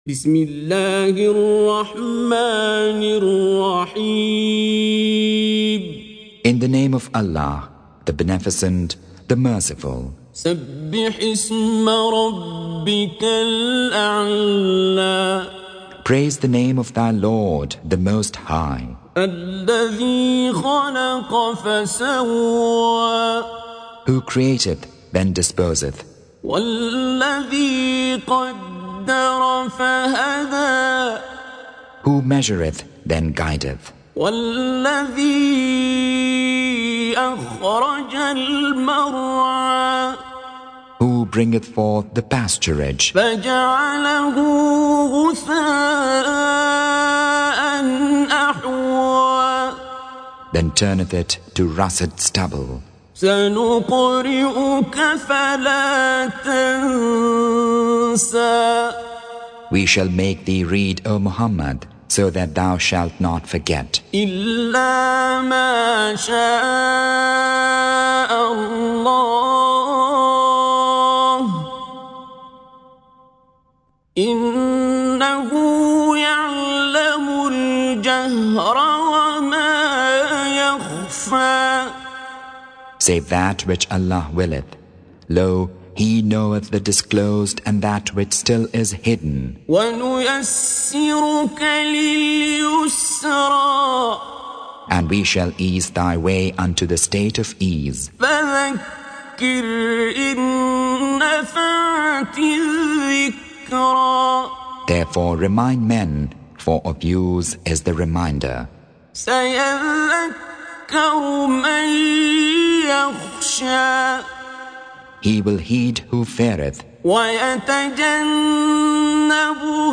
Surah Sequence تتابع السورة Download Surah حمّل السورة Reciting Mutarjamah Translation Audio for 87. Surah Al-A'l� سورة الأعلى N.B *Surah Includes Al-Basmalah Reciters Sequents تتابع التلاوات Reciters Repeats تكرار التلاوات